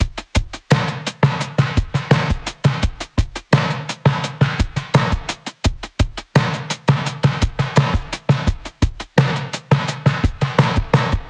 Old School Is Dead（DB-30 OFF / ON）
Old-School-Is-Dead-WET[662].mp3